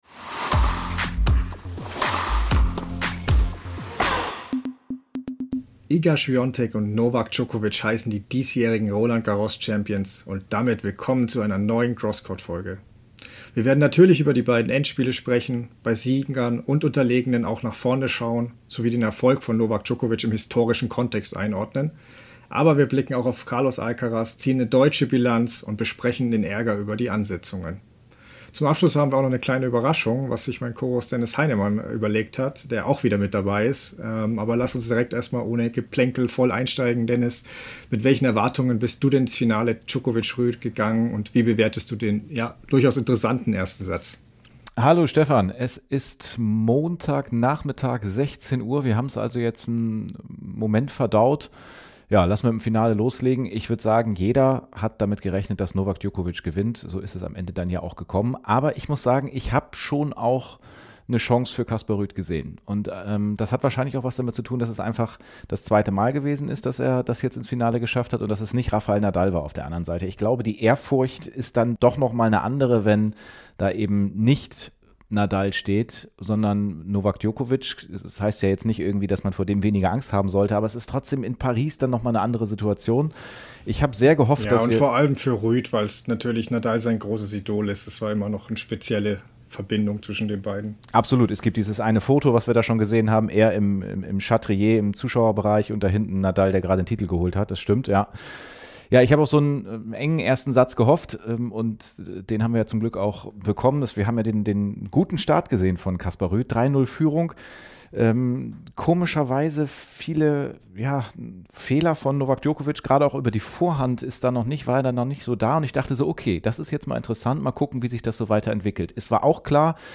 Entspannt und kompetent führen die Gastgeber durch den 60-minütigen Talk – und entlocken den Sportlern auch persönliche und hintergründige Antworten.